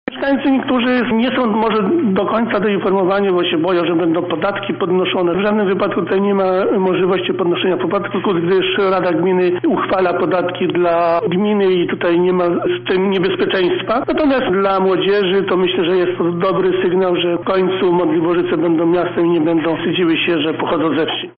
Od 1 stycznia natomiast tamtejsze instytucje gminne staną się miejskimi, a wójt zostanie burmistrzem. Czego w związku ze zmianami mogą spodziewać się mieszkańcy wyjaśnia Witold Kowalik – wójt gminy Modliborzyce.